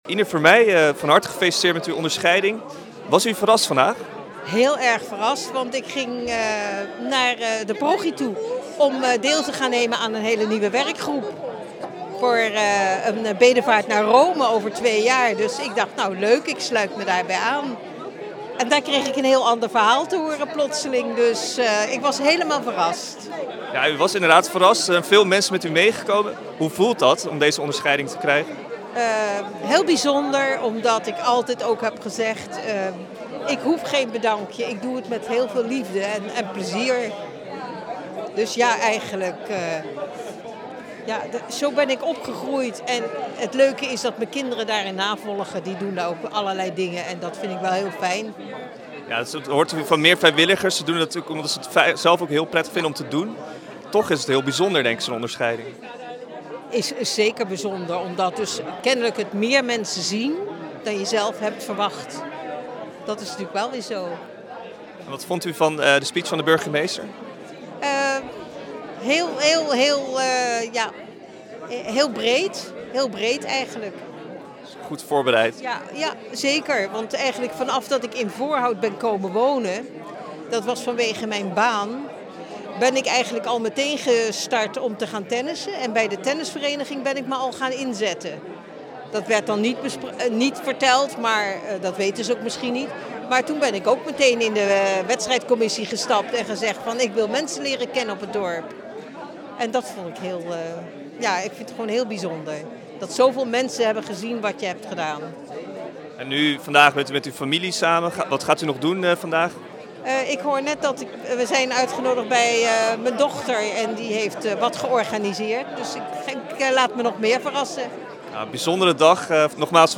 Het interview